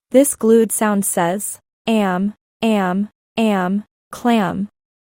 AM-clam-lesson-AI.mp3